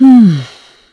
Isolet-Vox_Sigh1_kr.wav